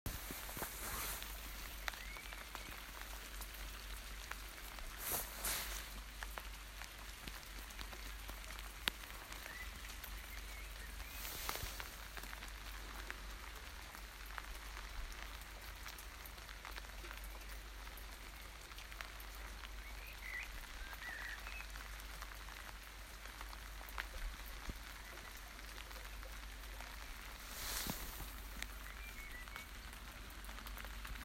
Still, I was up at 04.15 to greet the event. Some rain and a little dawn chorus from my resident blackbird: